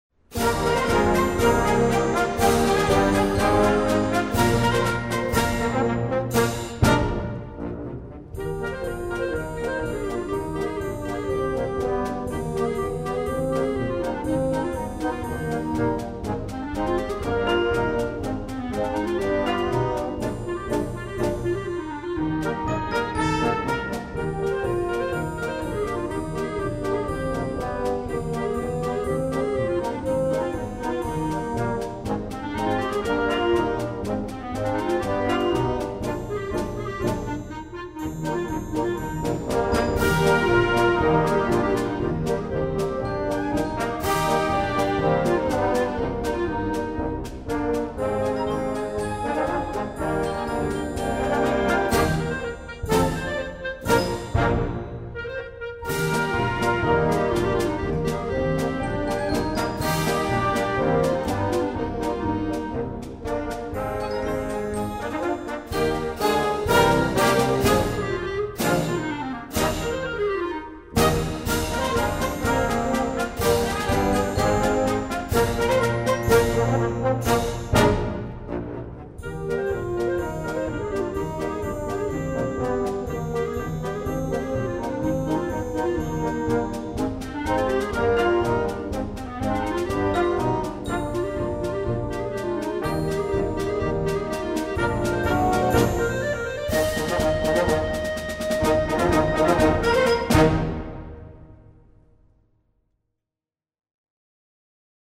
Voicing: Clarinet Section w/ Band